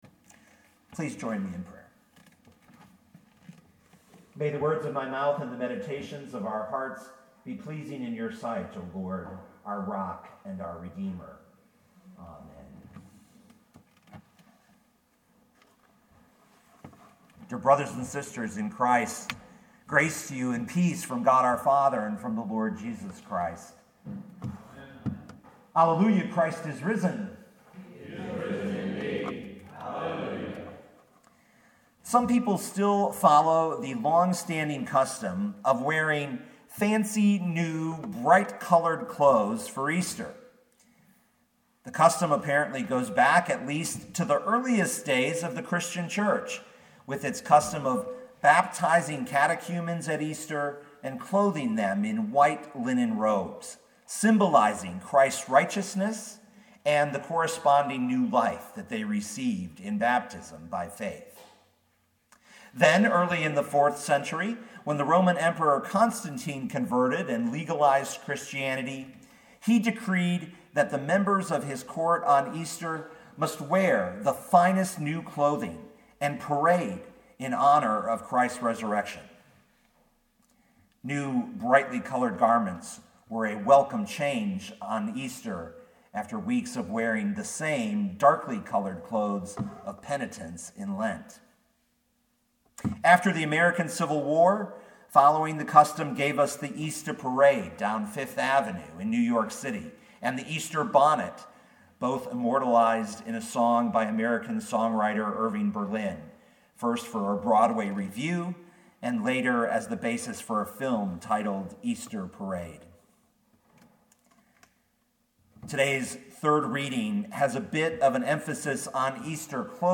2019 John 20:1-18 Listen to the sermon with the player below, or, download the audio.